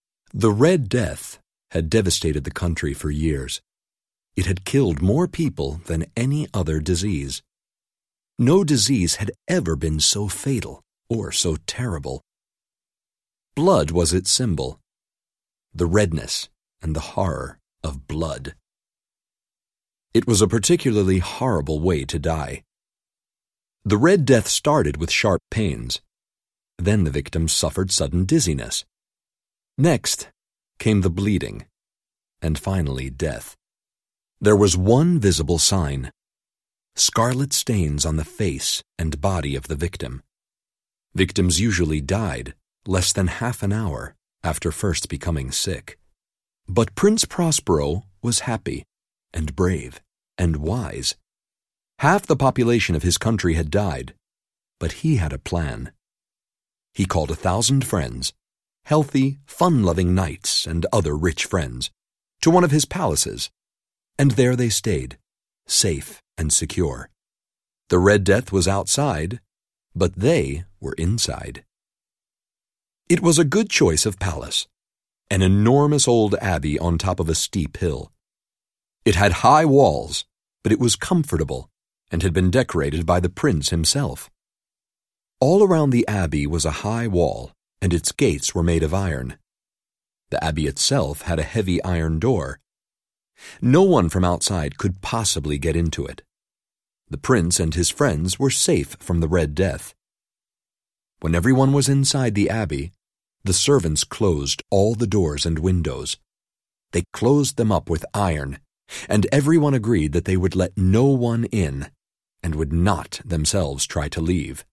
Stories of Mystery and Suspense (EN) audiokniha
Ukázka z knihy